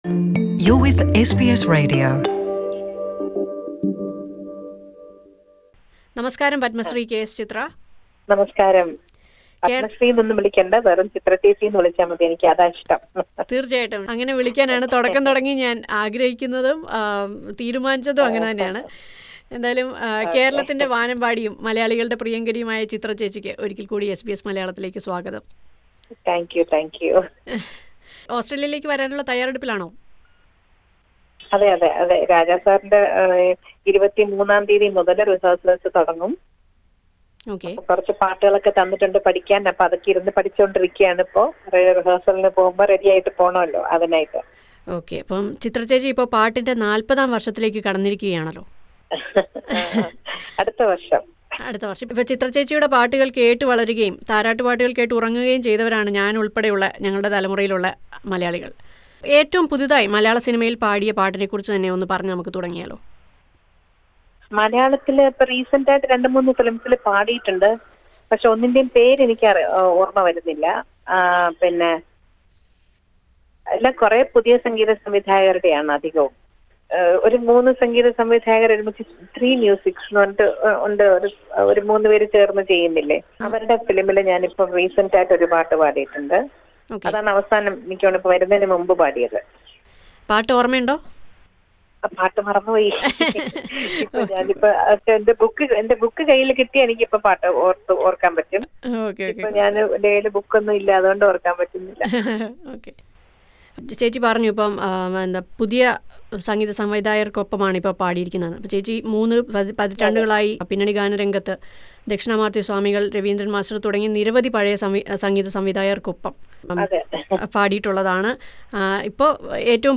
Ahead of her visit to Australia KS Chithra speaks to SBS Malayalam about her latest songs, the changes in music industry and so on. Chithra also opens her mind about her comments on singer Shreya Ghoshal which turned out to be a big controversy in the Indian music industry. Listen to the interview from the above player.